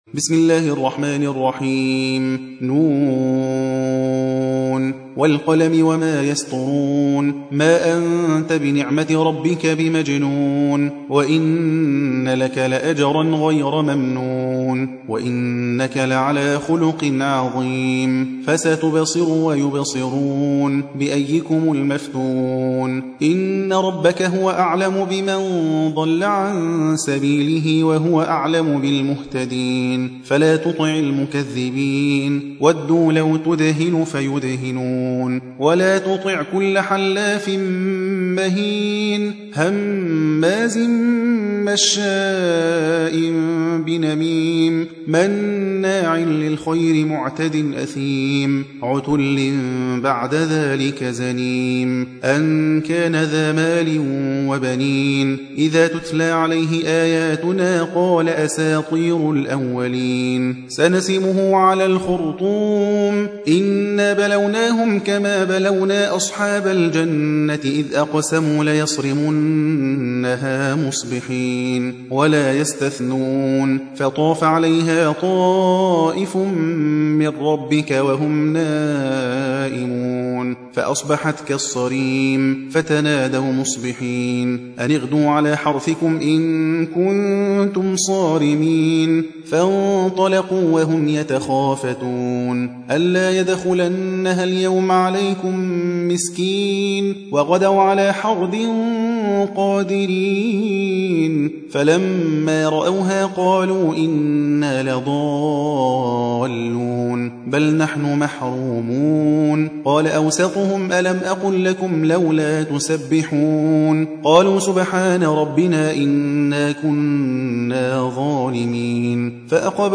68. سورة القلم / القارئ